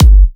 edm-kick-75.wav